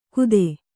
♪ kude